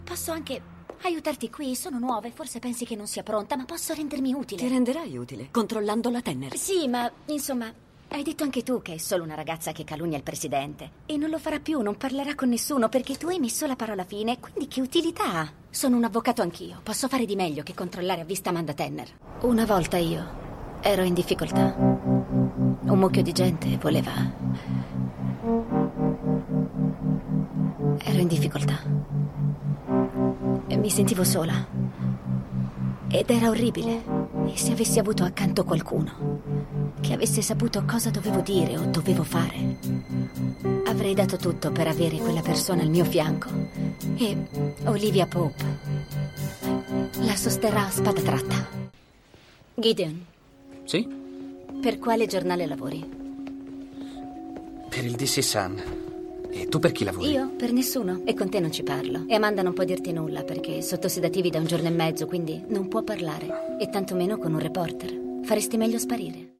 nel telefilm "Scandal", in cui doppia Katie Lowes.